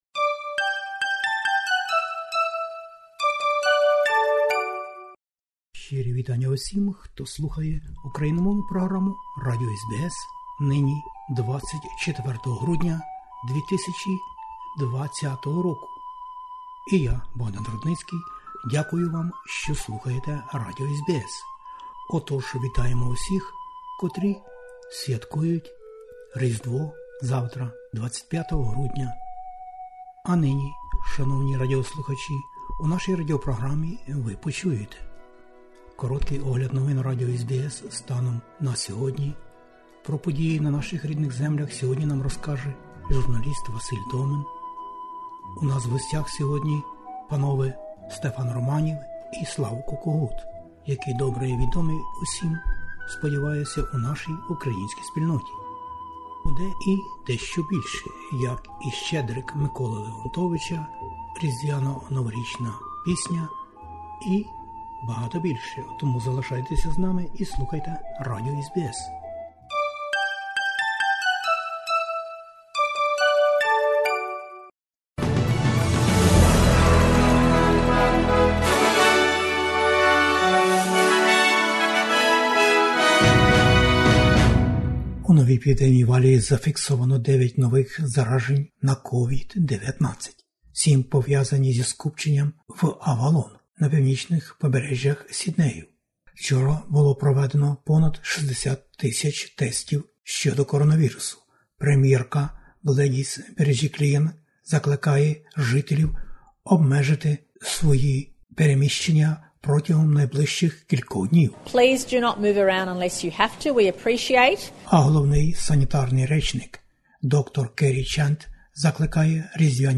SBS НОВИНИ УКРАЇНСЬКОЮ
SBS Ukrainian, 3 pm FM, TV Ch. 38 and 302, every Thursday Source: SBS